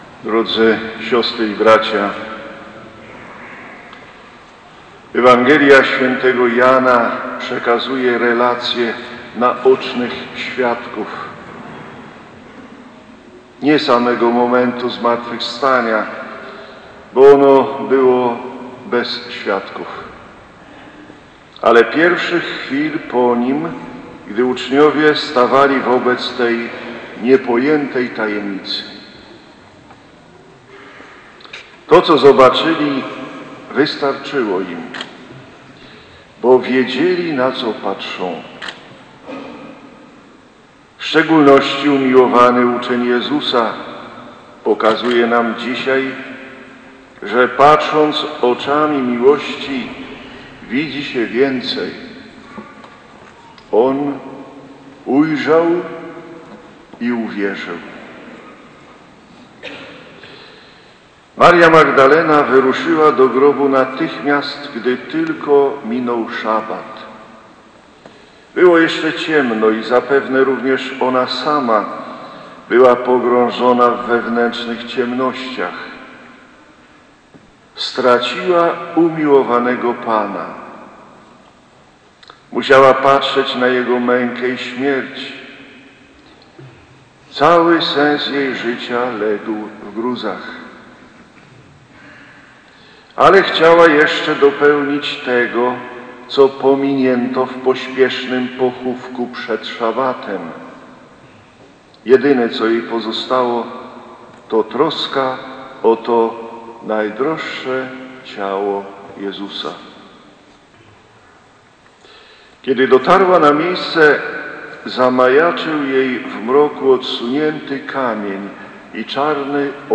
Msza św. Rezurekcyjna 2026 - homilia abpa Józefa Górzyńskiego
homilia-abp-Gorzynski.mp3